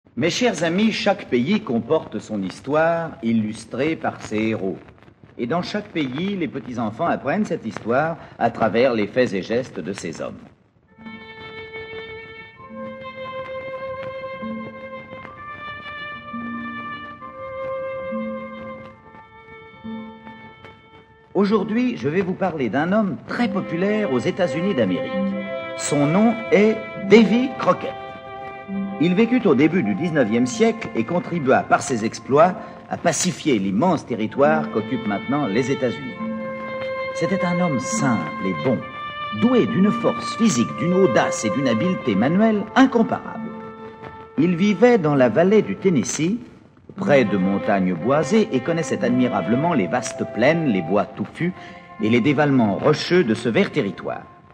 Cette aventure commence en 1828, près de la maison de Davy Crockett.... Davy Crockett : Serge Reggiani Récitant : François Perier
Enregistrement original de 1956 (extraits)